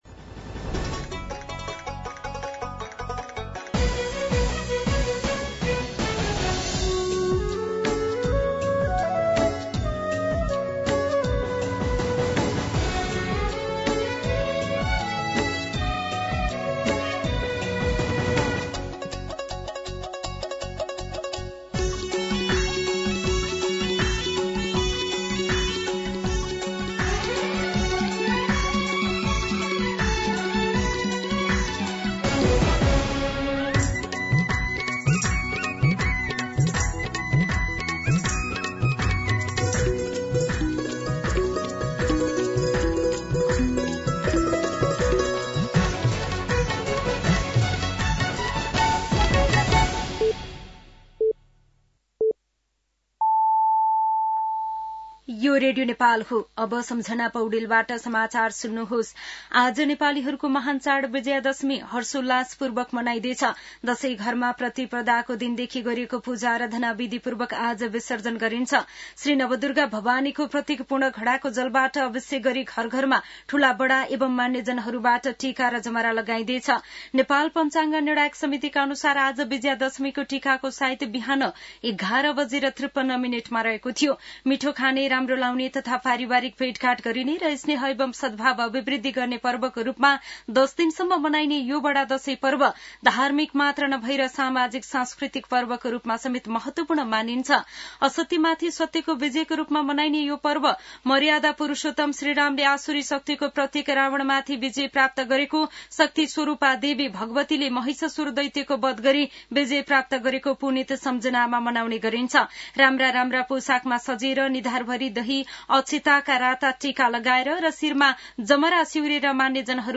दिउँसो १ बजेको नेपाली समाचार : १६ असोज , २०८२
1-pm-Nepali-News.mp3